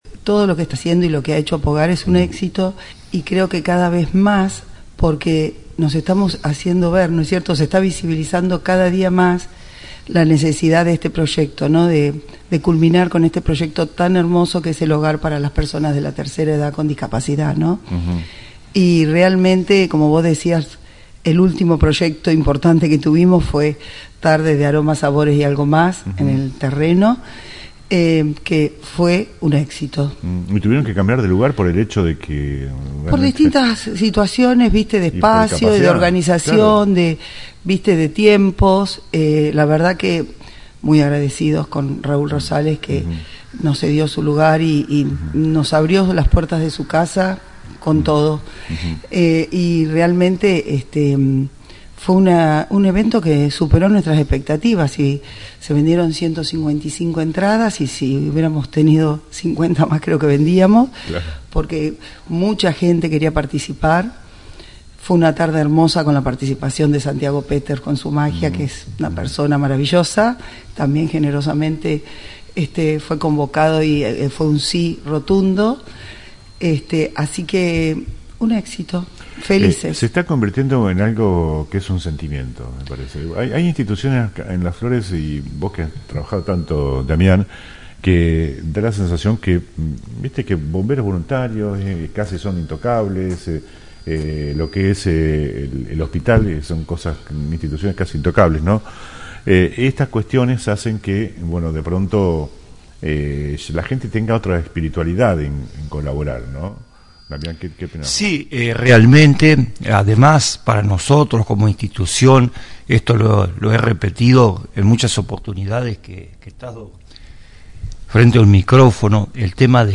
AUDIO DE LA ENTREVISTA COMPLETA